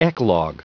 Prononciation du mot eclogue en anglais (fichier audio)
eclogue.wav